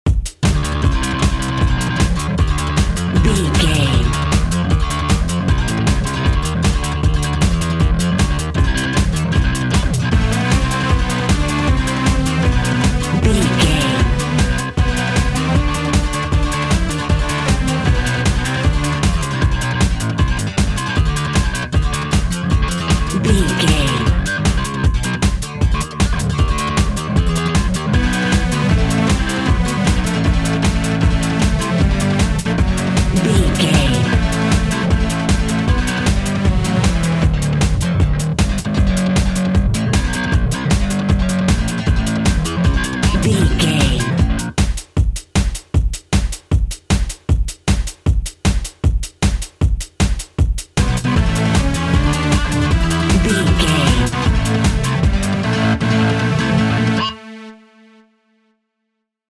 Techno Music.
Aeolian/Minor
Fast
futuristic
hypnotic
industrial
driving
energetic
frantic
dark
drums
electic guitar
synthesiser
drum machine